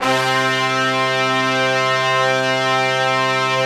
C3 POP BRASS.wav